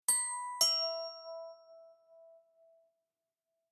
effet sonore